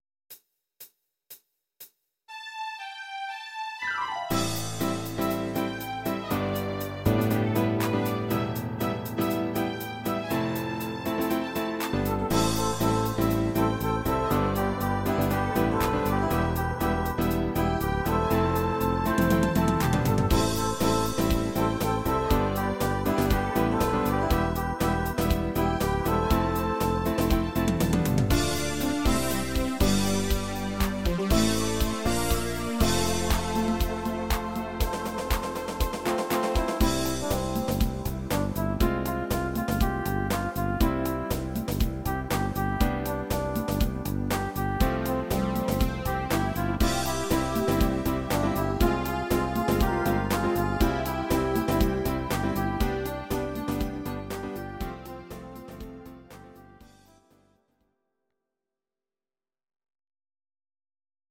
Audio Recordings based on Midi-files
Rock, 1980s